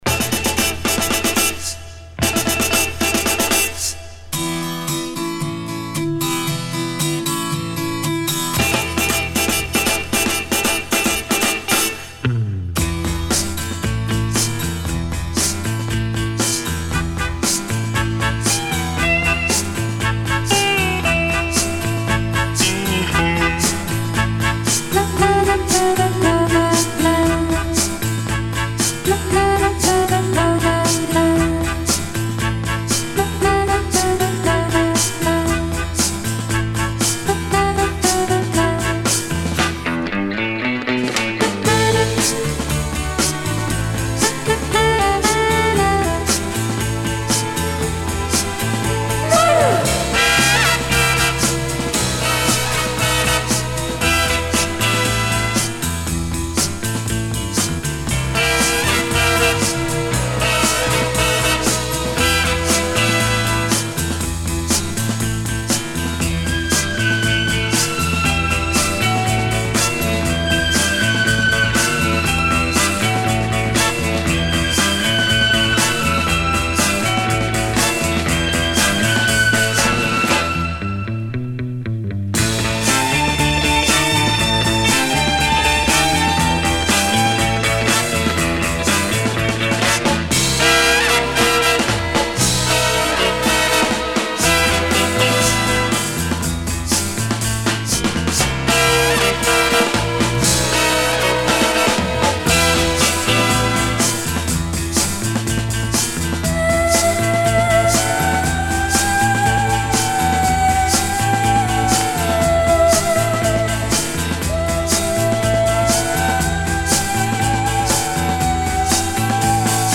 Всюду звучат, затаившие дыхание, женские вокалы.
Genre: Instrumental Pop, Back Vocal,
Easy Listening